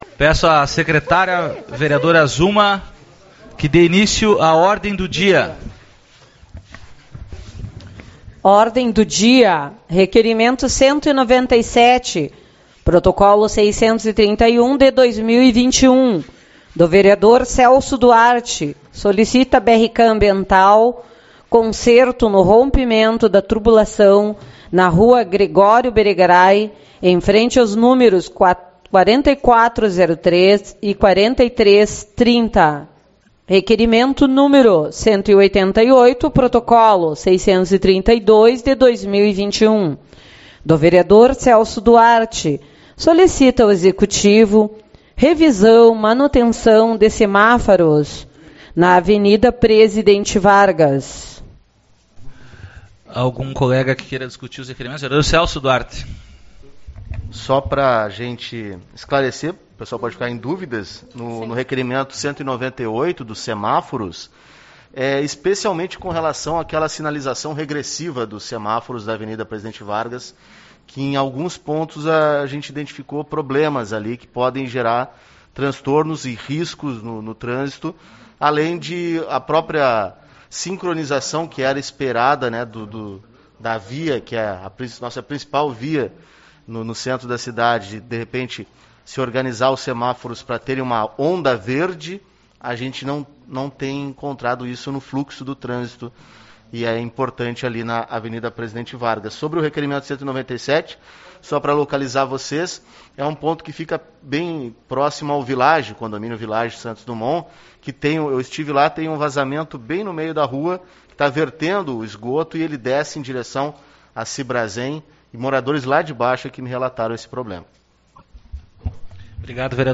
17/06 - Reunião Ordinária